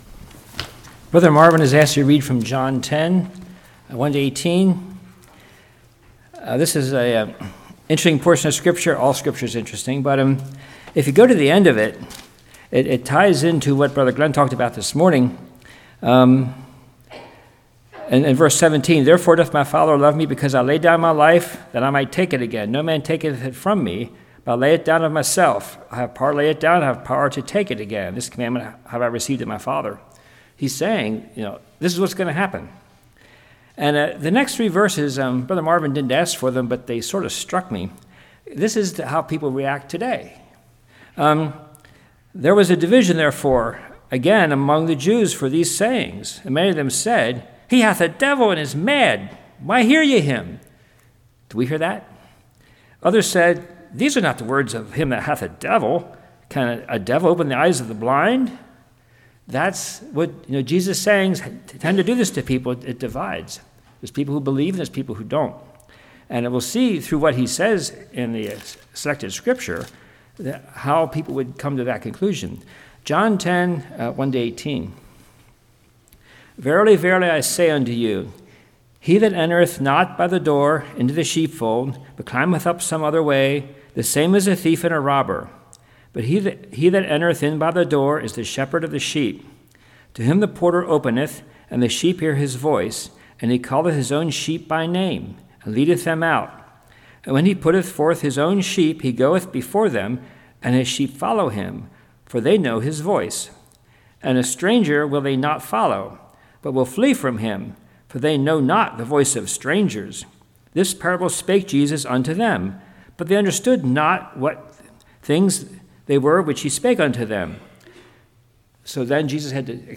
John 10:1-18 Service Type: Evening God provides for us